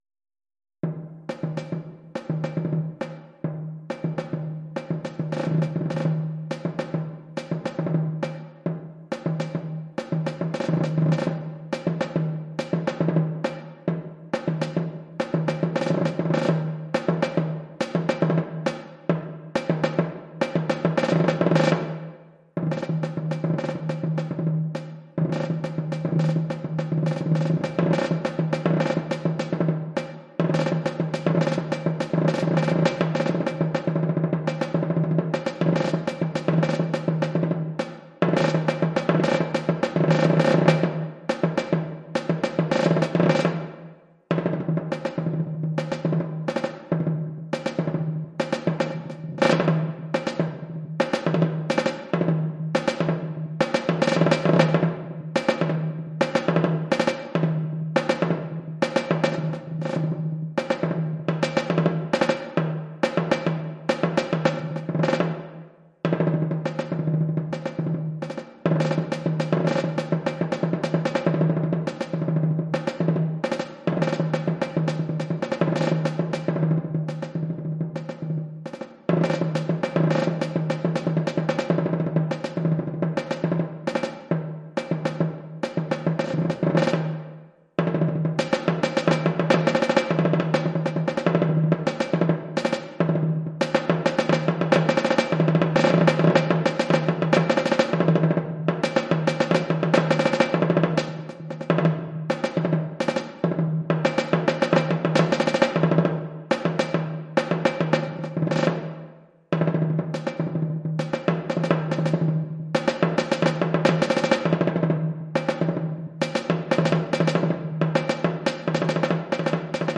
Oeuvre pour tambour seul.